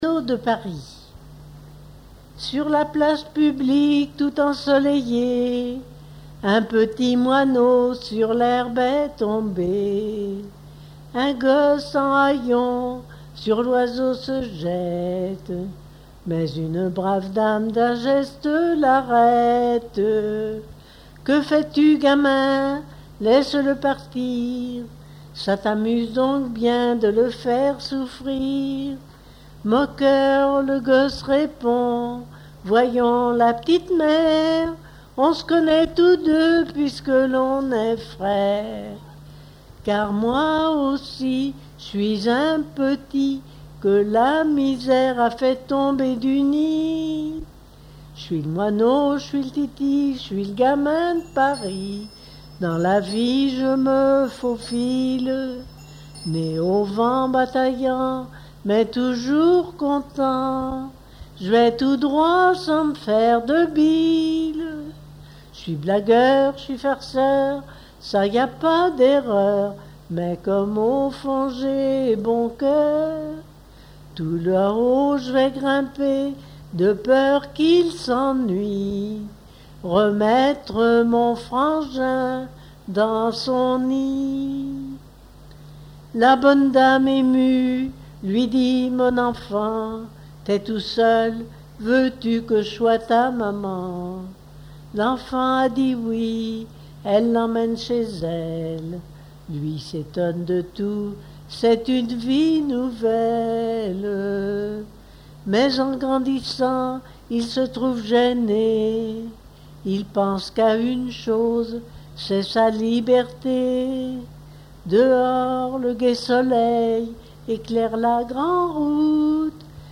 Genre strophique
Enquête Arexcpo en Vendée-C.C. Rocheservière
Catégorie Pièce musicale inédite